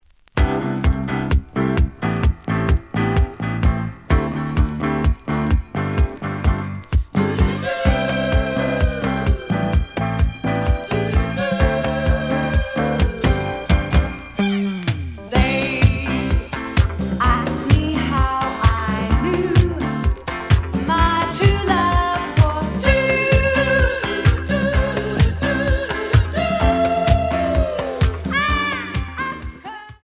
(Mono)
試聴は実際のレコードから録音しています。
●Genre: Disco
●Record Grading: VG+ (盤に若干の歪み。